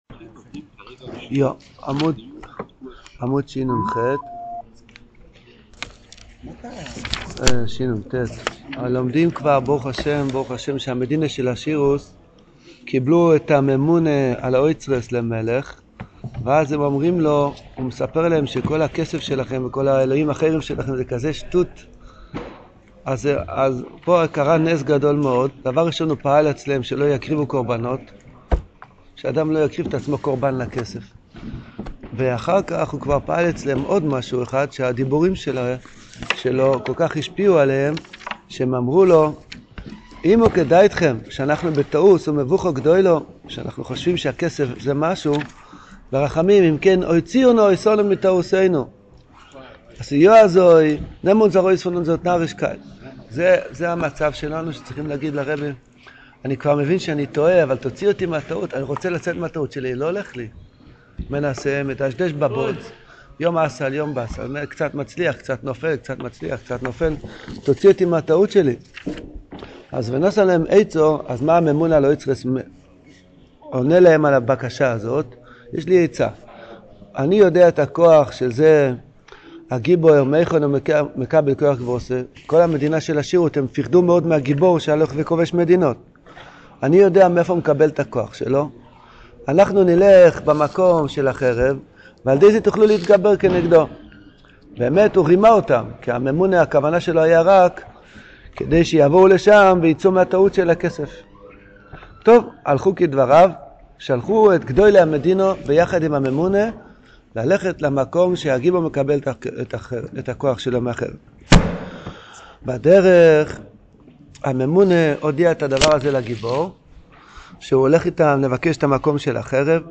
This shiur is given daily after shachris and is going through each of the stories in sipurei maasios in depth. The audio quality gets better after episode 26.